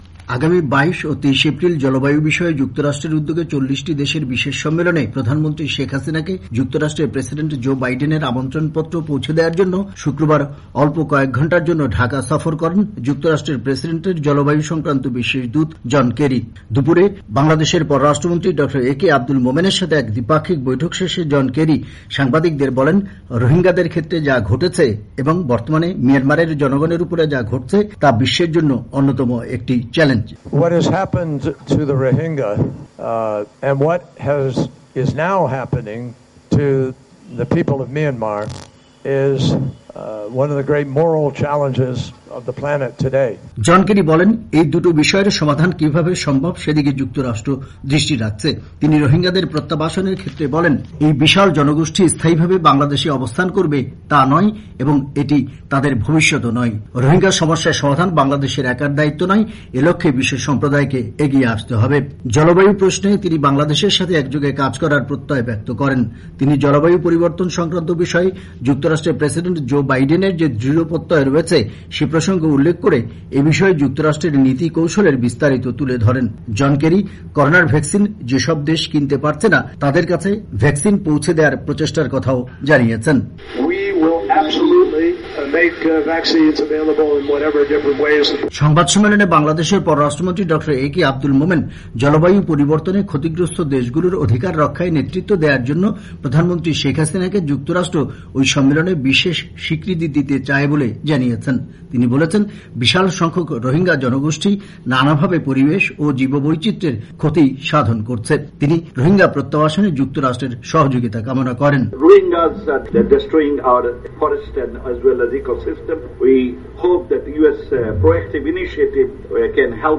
সংবাদ সম্মেলনে বাংলাদেশের পররাষ্ট্রমন্ত্রী ড. এ কে আব্দুল মোমেন জলবায়ু পরিবর্তনে ক্ষতিগ্রস্ত দেশগুলোর অধিকার রক্ষায় নেতৃত্ব দেয়ার জন্য প্রধানমন্ত্রী শেখ হাসিনাকে যুক্তরাষ্ট্র ওই সম্মেলনে বিশেষ স্বীকৃতি দিতে চায় বলে জানিয়েছেন।